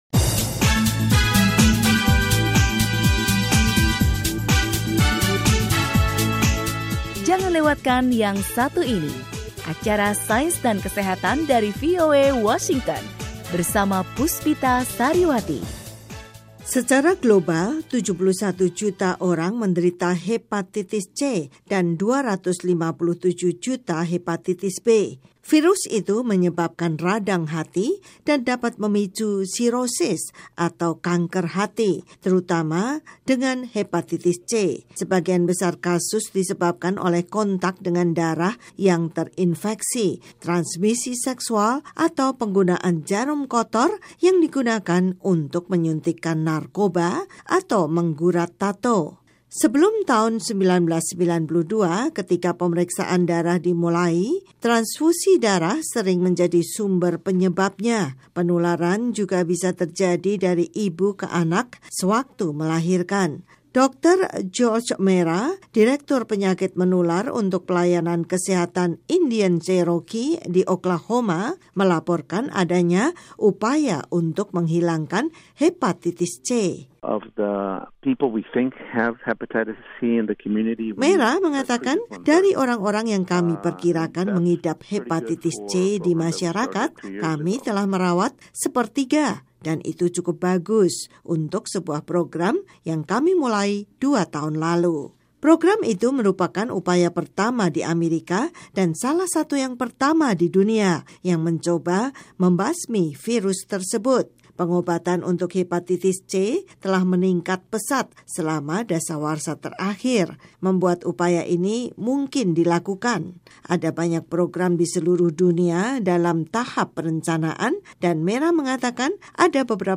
Banyak penduduk asli kemungkinanya 2 sampai 5 kali lebih besar terkena virus hepatitis dibanding populasi umum. Laporan dari Konferensi Masyarakat Asli Sedunia tentang Viral Hepatitis di Anchorage, Alaska, memperagakan upaya untuk membasmi hepatitis C.